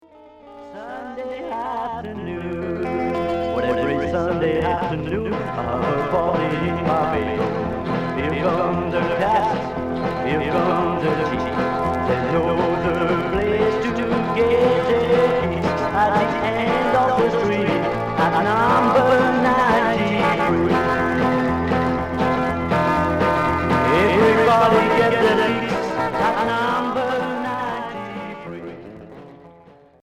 Rockabilly Premier 45t retour à l'accueil